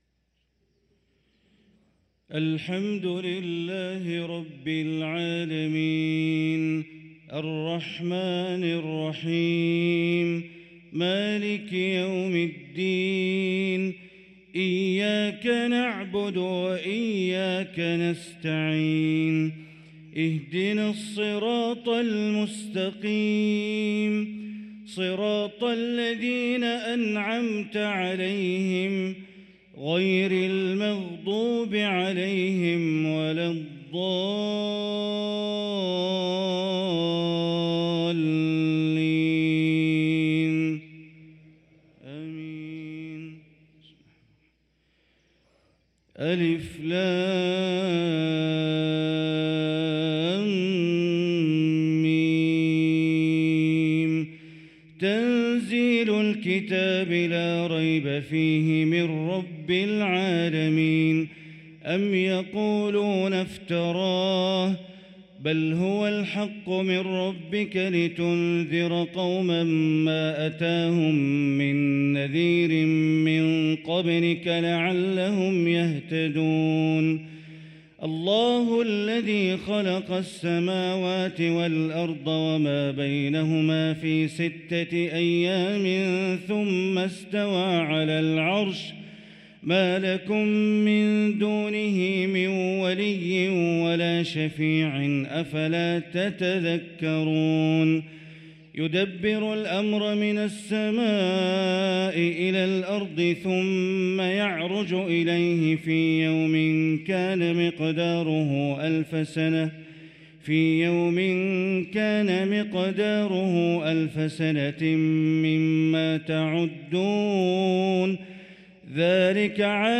صلاة الفجر للقارئ بندر بليلة 3 جمادي الأول 1445 هـ
تِلَاوَات الْحَرَمَيْن .